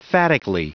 Prononciation du mot phatically en anglais (fichier audio)
Prononciation du mot : phatically